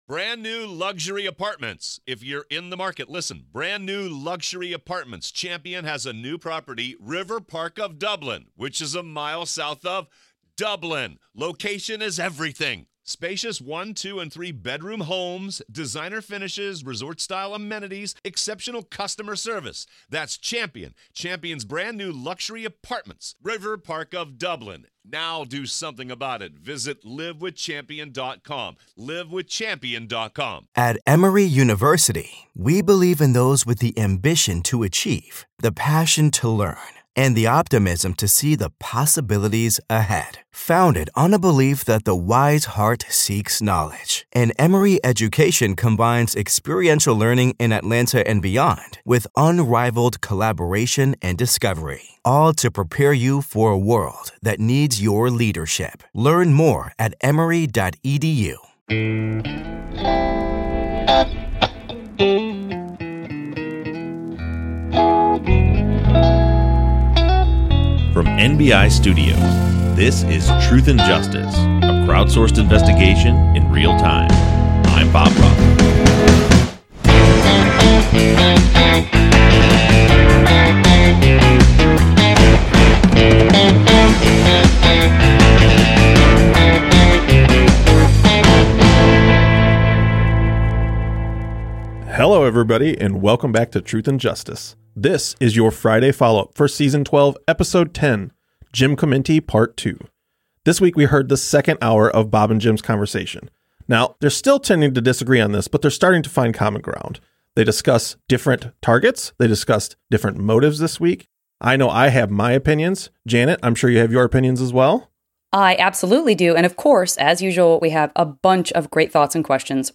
True Crime, Documentary, Society & Culture